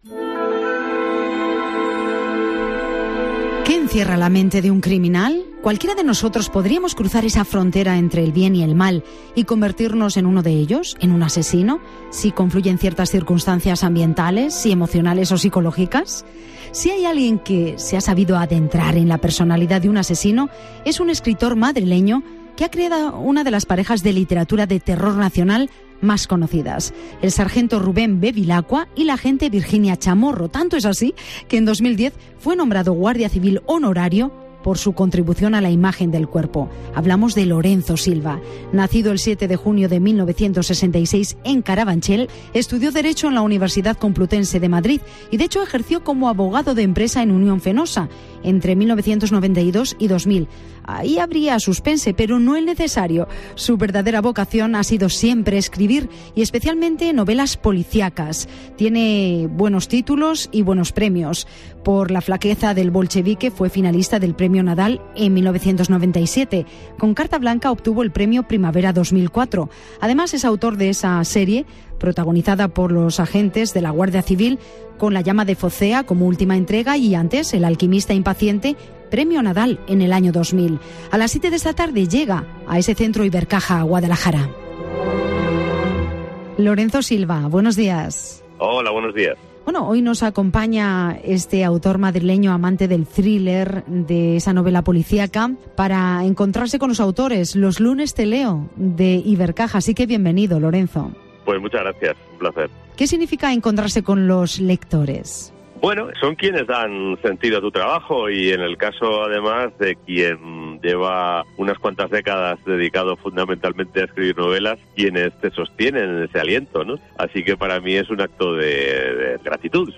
Entrevista a Lorenzo Silva en Mediodía COPE Guadalajara